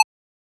edm-perc-04.wav